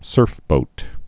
(sûrfbōt)